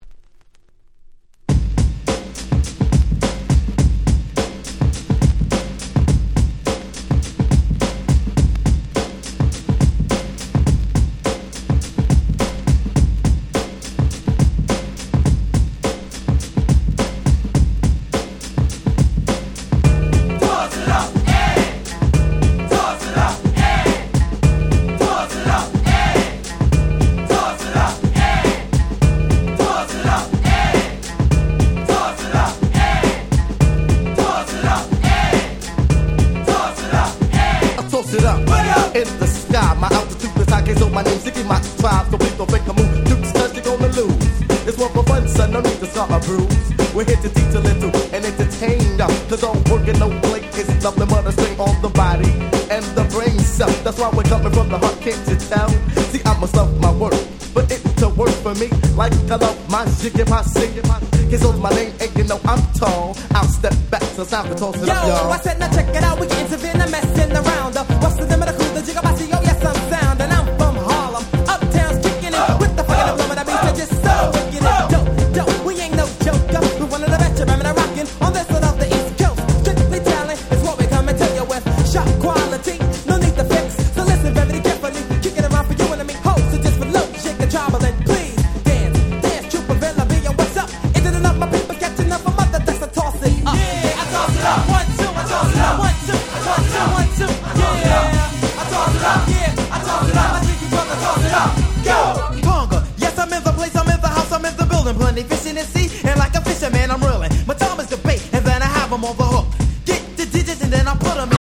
92' Nice Remix !!